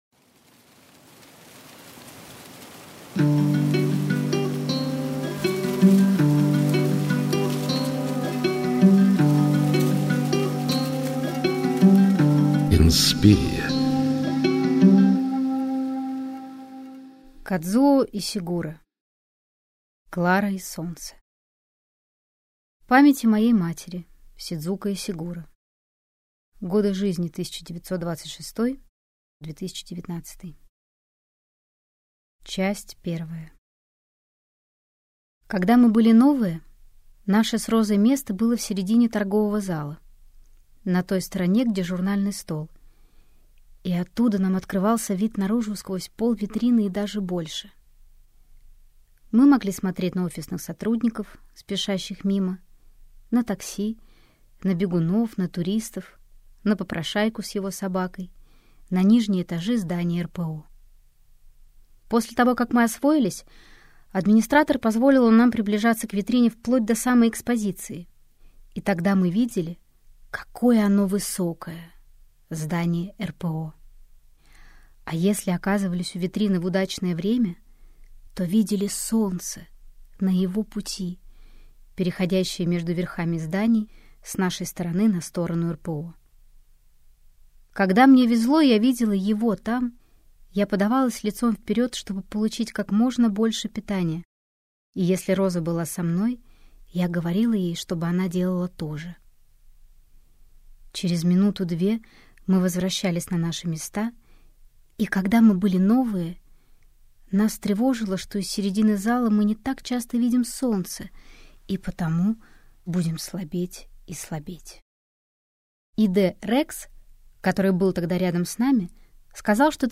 Аудиокнига Клара и Солнце - купить, скачать и слушать онлайн | КнигоПоиск